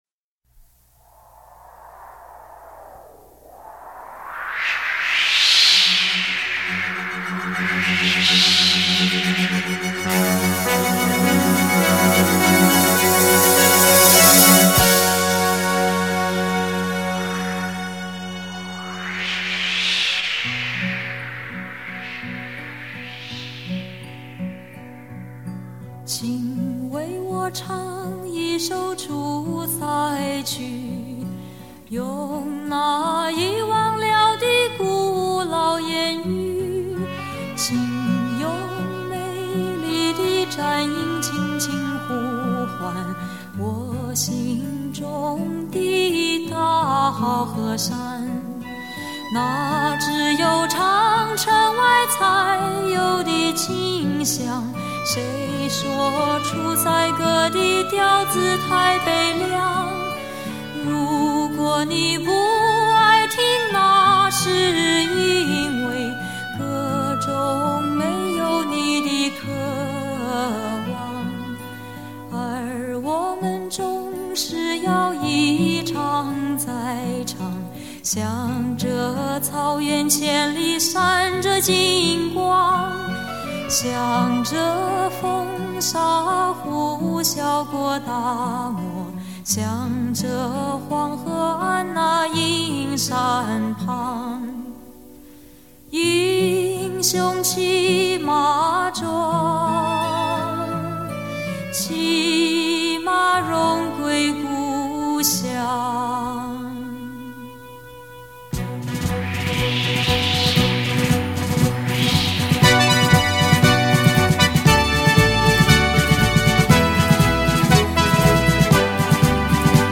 民谣风系列